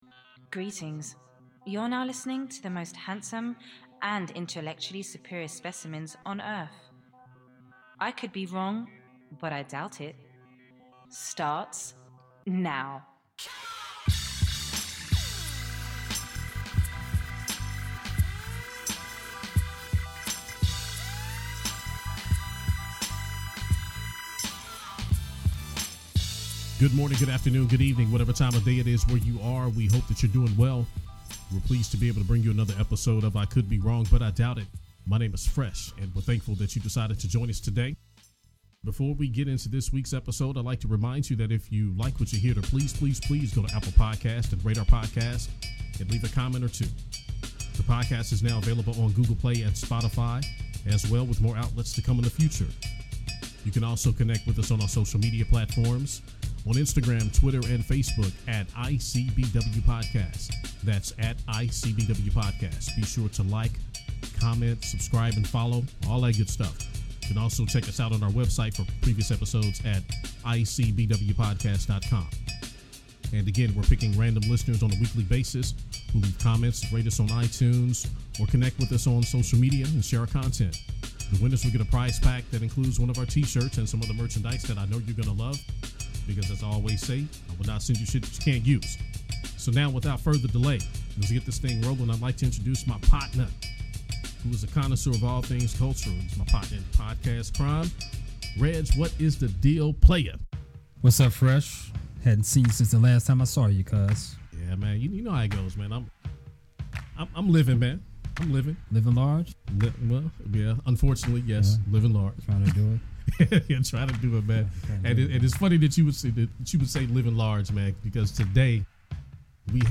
Healthcare discussion